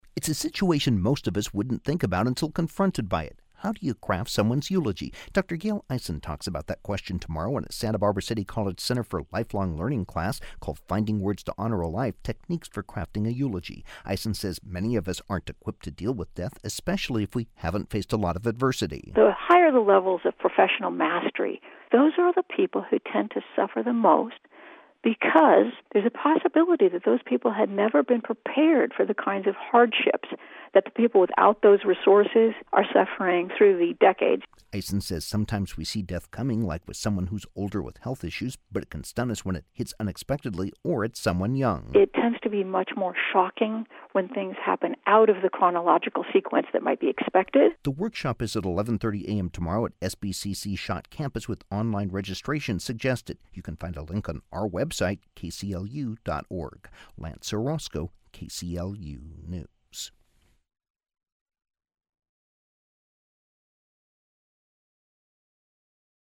NPR-Affiliate Radio Interview, June 2014.
NPR-Affiliate-Radio-Interview-June-2014..mp3